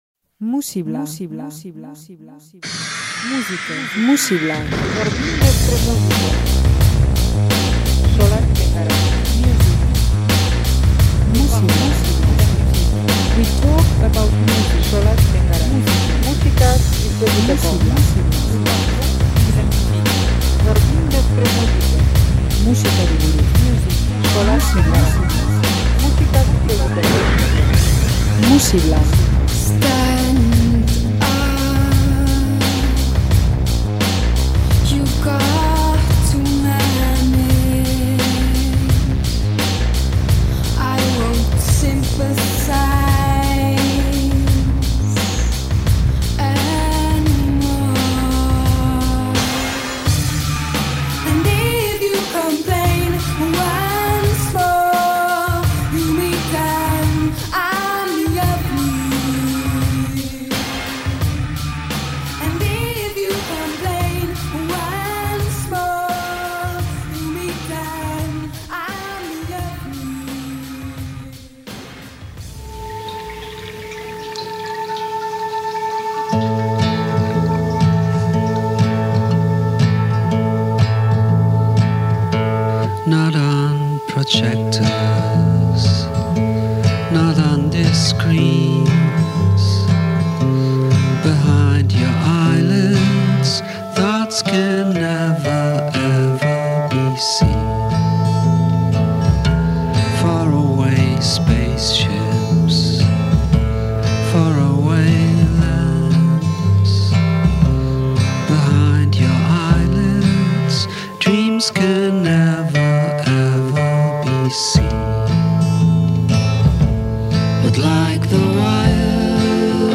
power pop eta slacker rock giroan mugitzen da
kantu labur eta zuzenez osatutako bilduma daukagu